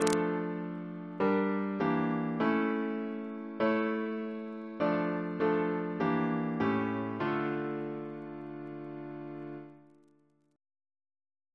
Single chant in D minor Composer: F. A. Gore Ouseley (1825-1889) Reference psalters: ACP: 125